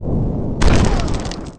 torpedo.mp3